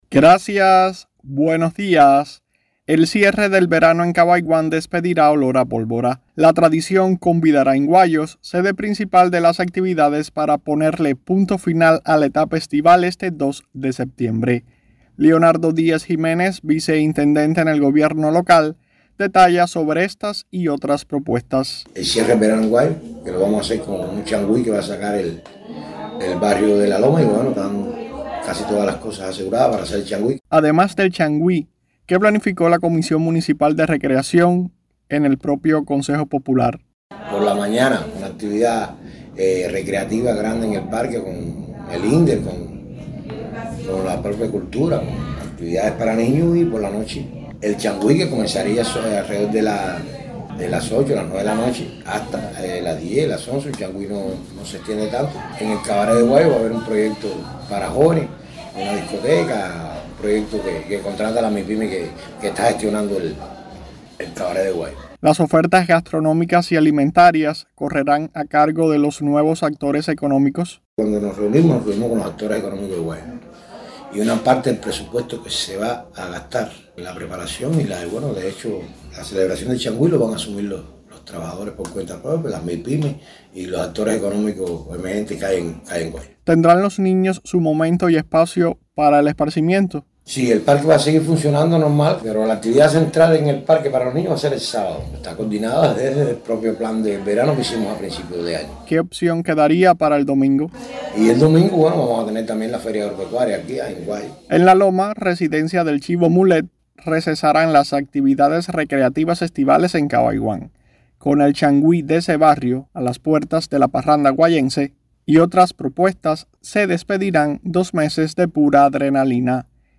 La tradición convidará en Guayos, sede principal de las actividades para poner punto final a la etapa estival este 2 de septiembre. Leonardo Díaz Jiménez, vice intendente en el gobierno local, detalla sobre estas y otras propuestas.